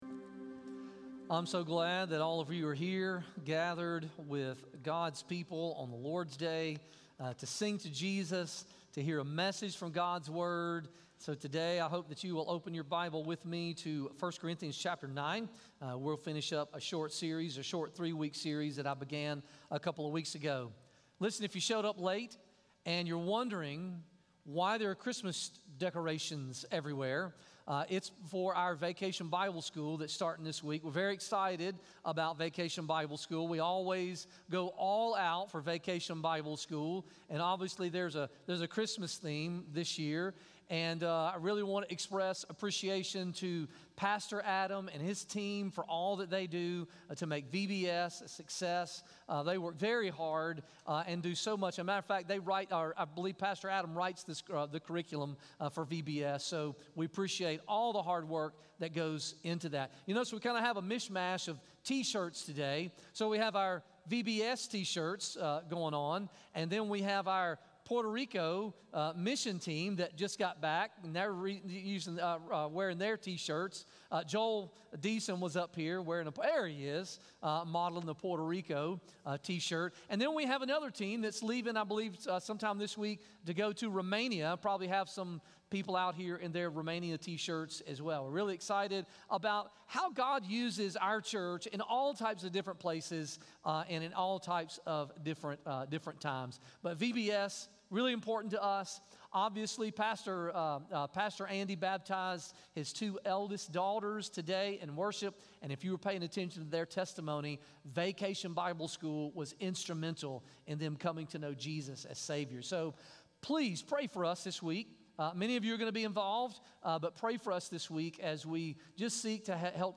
Sermons from Stevens Street Baptist Church, located at 327 West Stevens Street in Cookeville, TN.